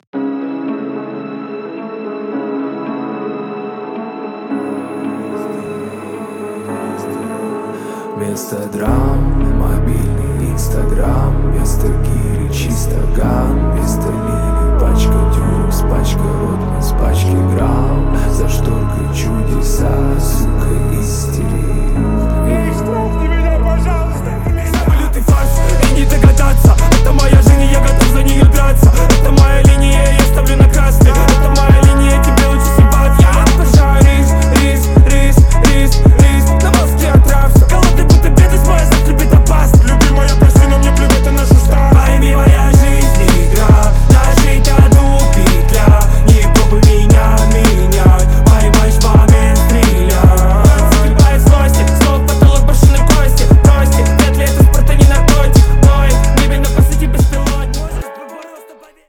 • Качество: 320, Stereo
громкие
русский рэп
басы
качающие